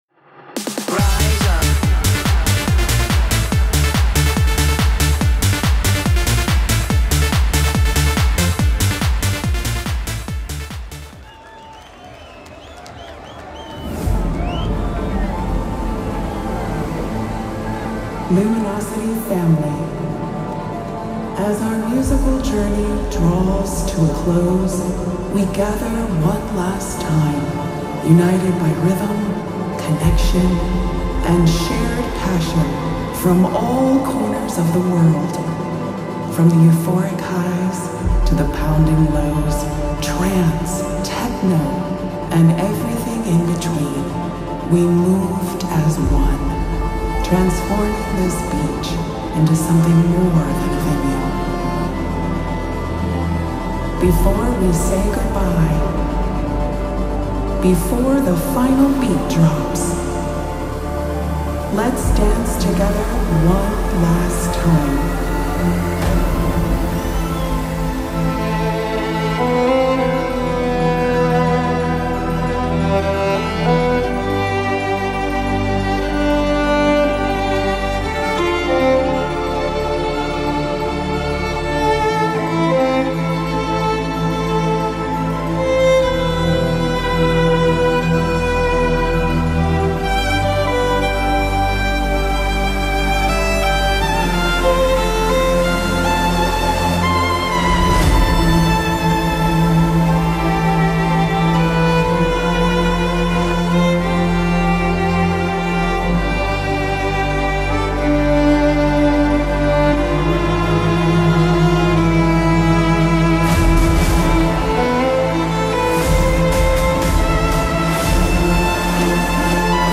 (Violin Outro)